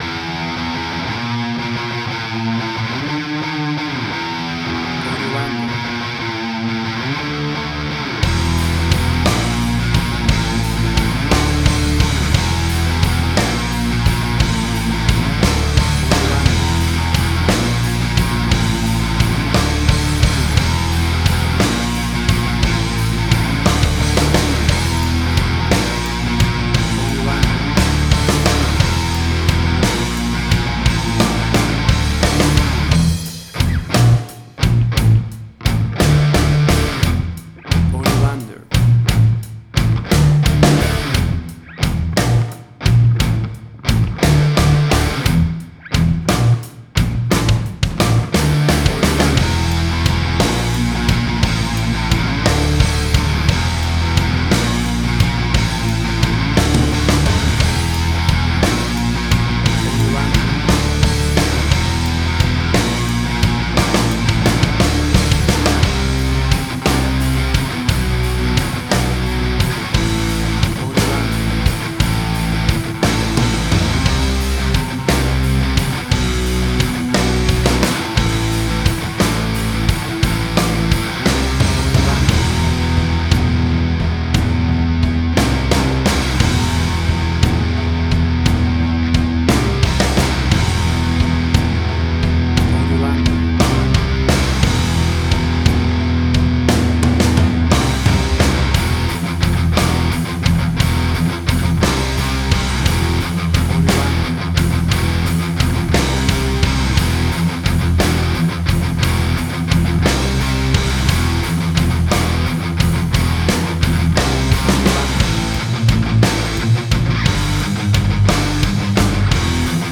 Hard Rock, Similar Black Sabbath, AC-DC, Heavy Metal.
Tempo (BPM): 58